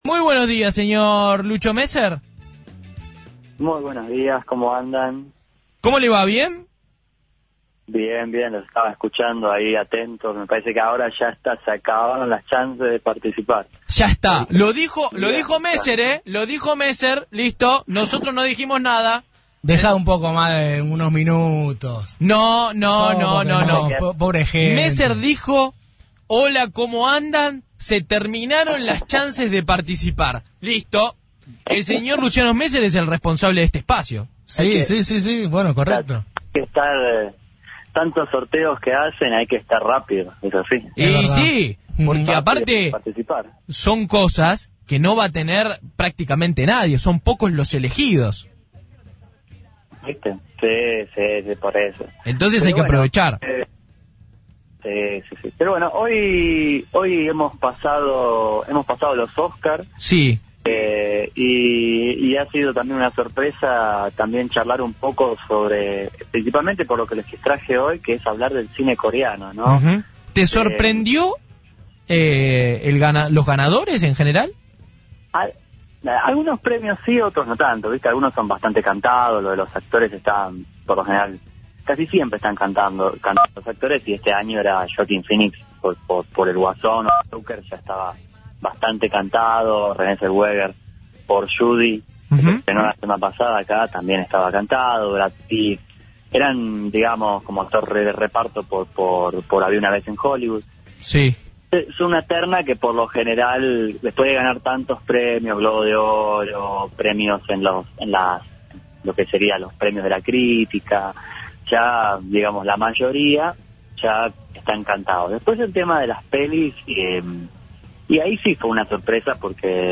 En dialogo con FRECUENCIA ZERO